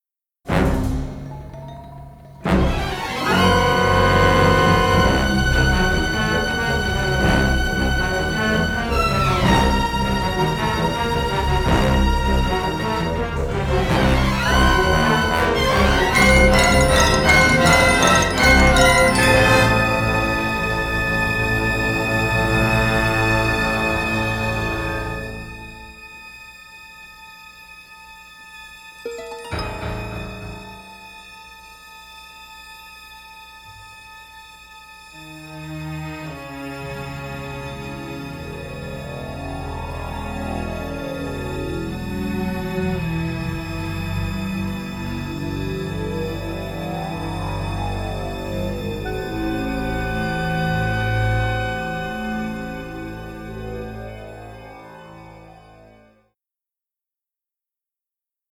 Dynamic orchestral score